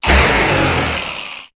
Cri de Minotaupe dans Pokémon Épée et Bouclier.